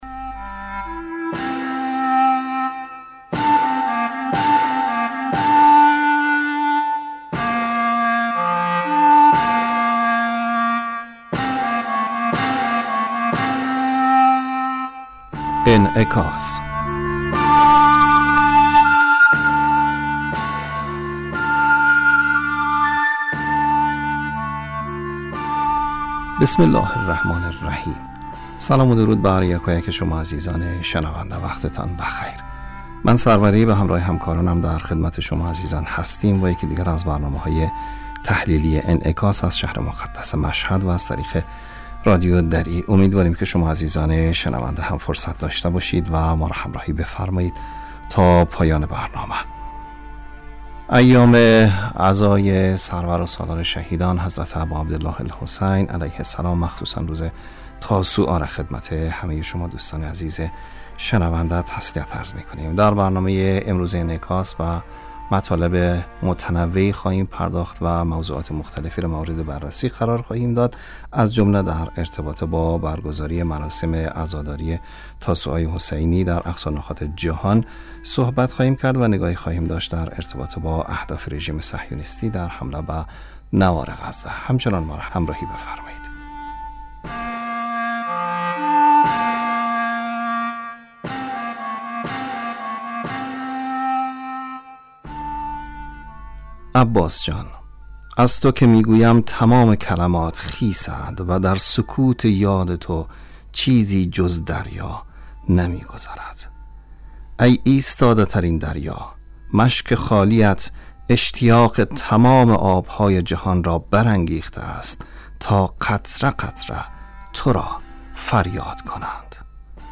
برنامه انعکاس به مدت 35 دقیقه هر روز در ساعت 18:50 بعد ظهر بصورت زنده پخش می شود. این برنامه به انعکاس رویدادهای سیاسی، فرهنگی، اقتصادی و اجتماعی مربوط به افغانستان و تحلیل این رویدادها می پردازد.